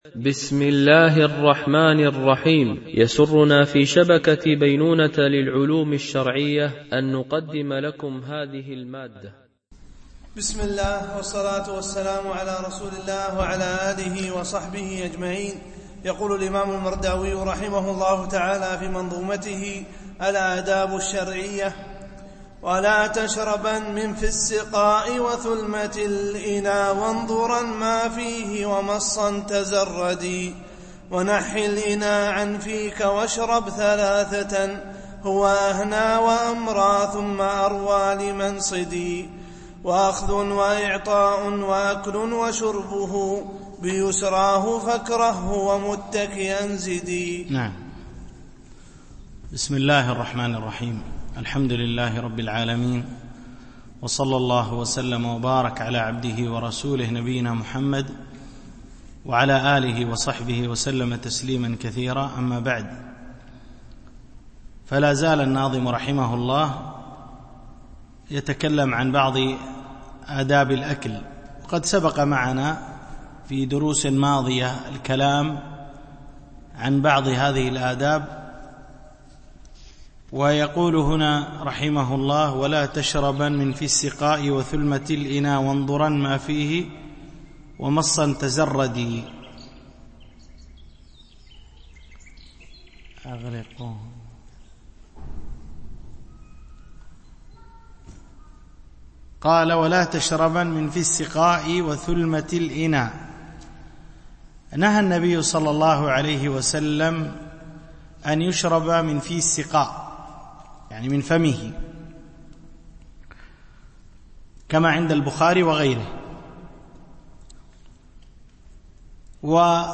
شرح منظومة الآداب الشرعية – الدرس25 ( الأبيات 367-380 )